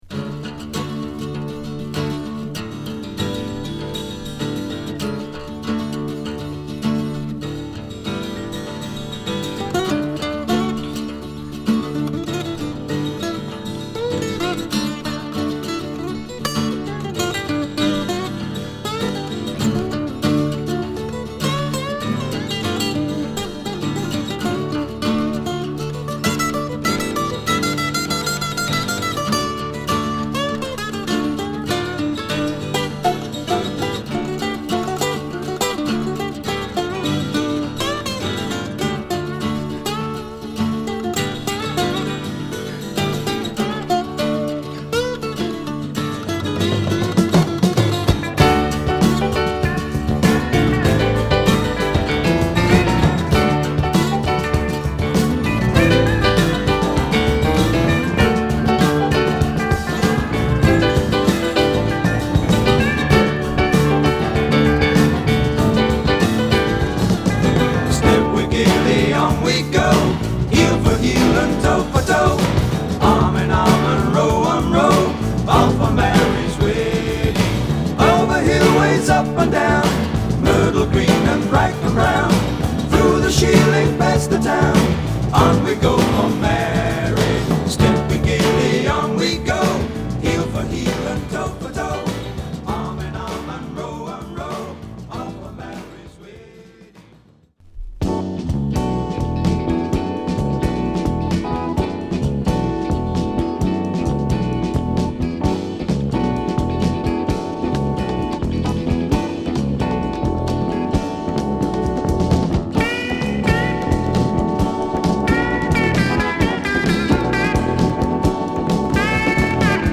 爽やかなフォーキーサウンドと清涼感あるピアノが絡む気持ち良いA2